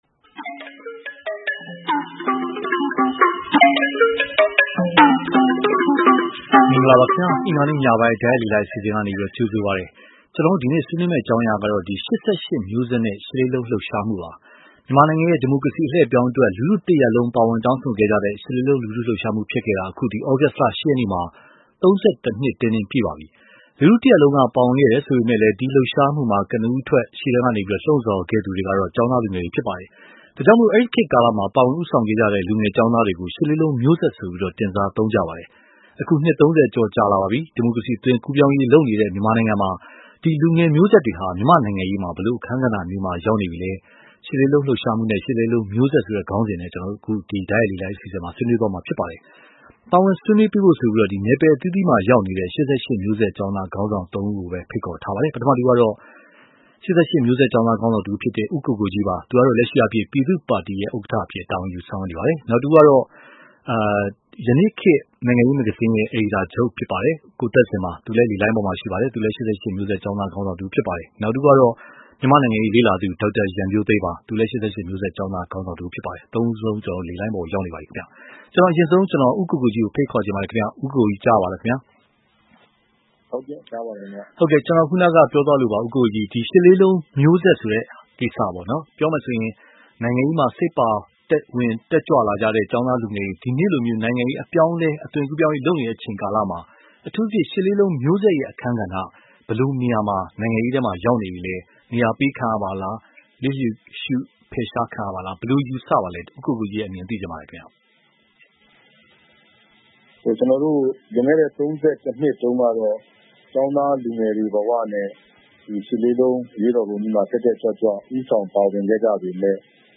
ရှစ်လေးလုံးလှုပ်ရှားမှုနဲ့ ၈၈ ကျောင်းသားမျိုးဆက် (တိုက်ရိုက်လေလှိုင်း)